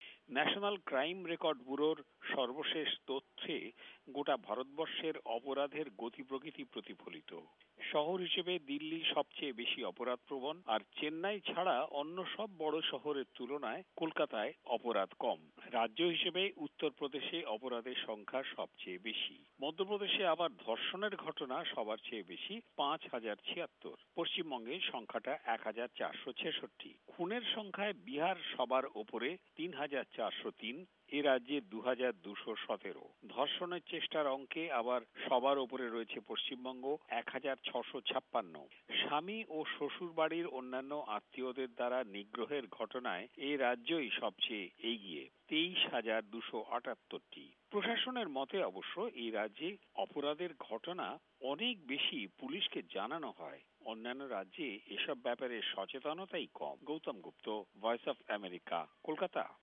ভারতে অপরাধ মূলক ঘটনার বিষয়ে রিপোর্ট